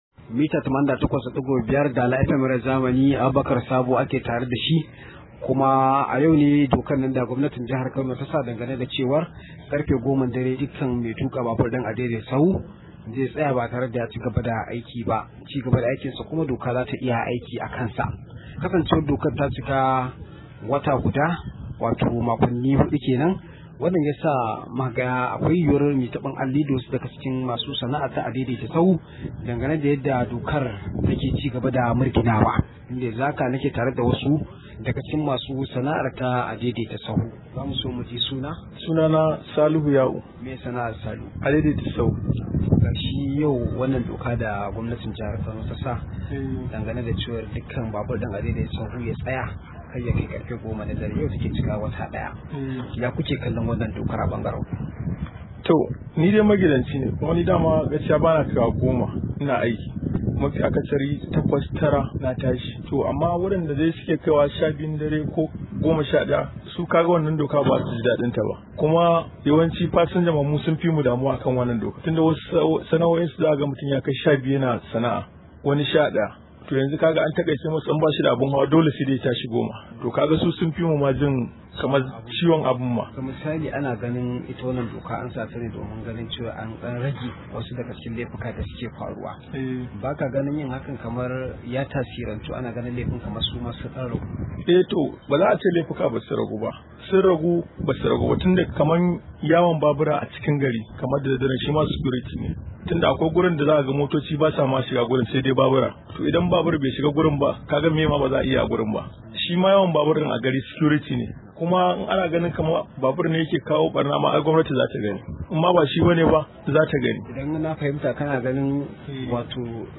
Rahoto: Wata guda kenan da dokar hana tukin Adaidaita Sahu ta fara aiki